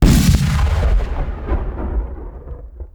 beamCannon.wav